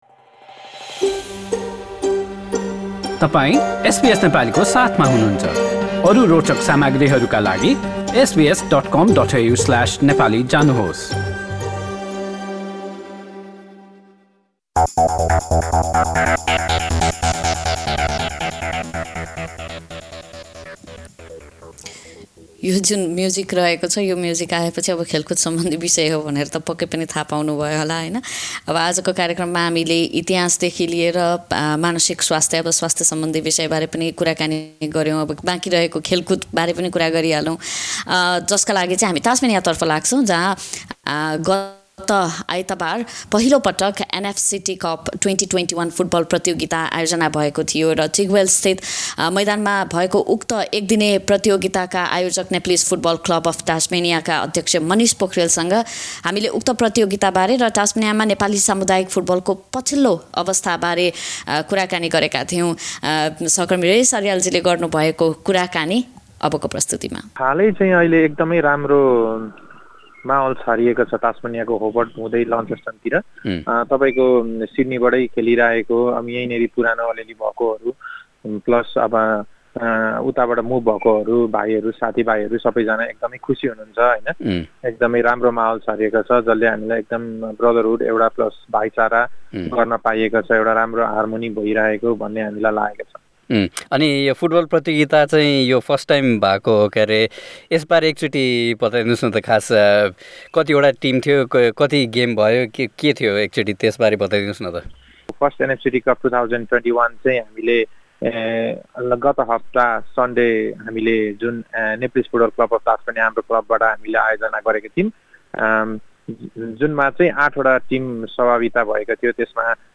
match report